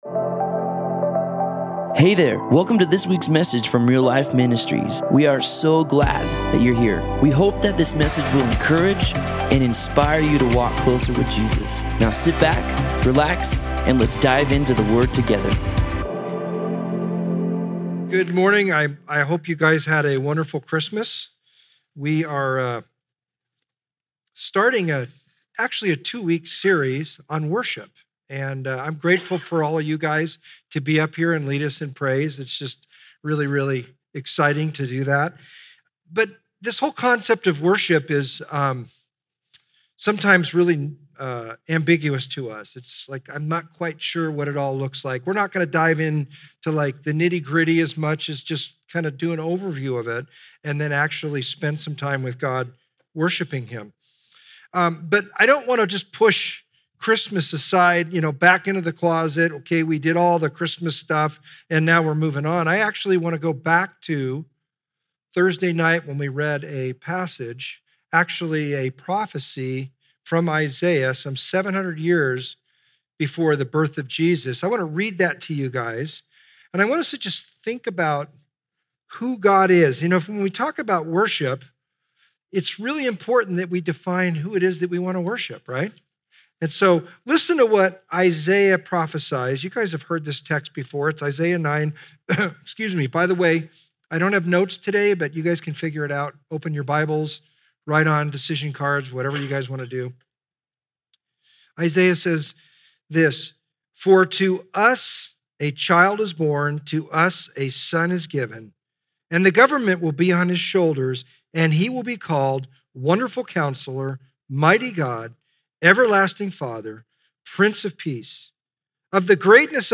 North+Campus+Dec+27th+Sermon.mp3